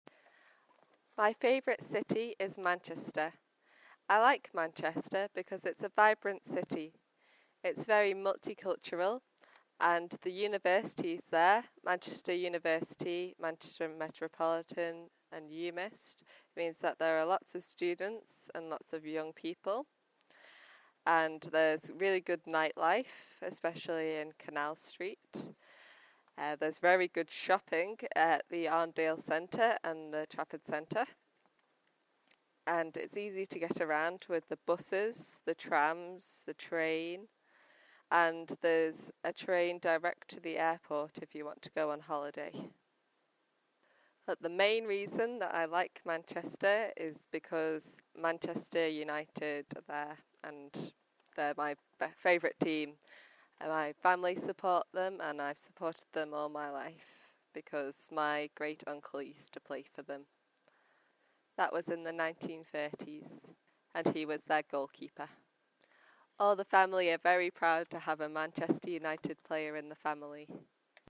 Ile de Man